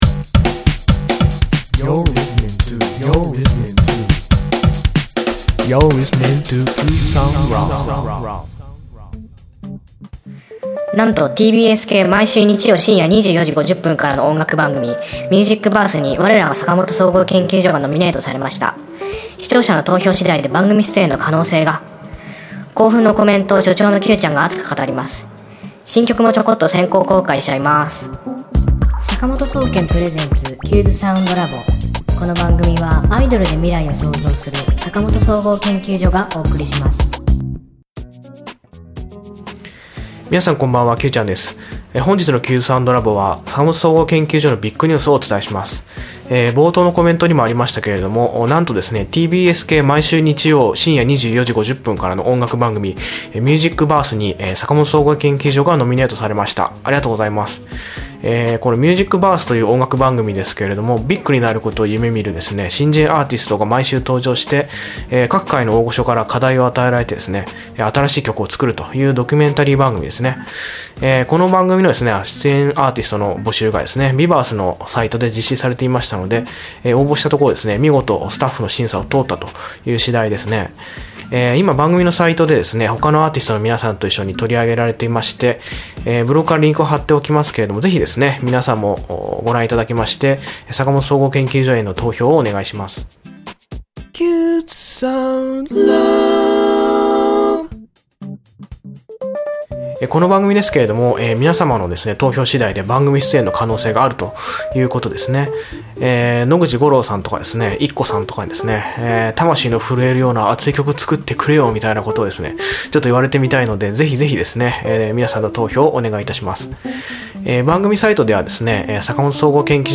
今週の挿入歌